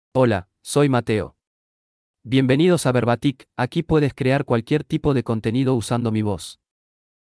MaleSpanish (Uruguay)
Mateo — Male Spanish AI voice
Voice sample
Male
Mateo delivers clear pronunciation with authentic Uruguay Spanish intonation, making your content sound professionally produced.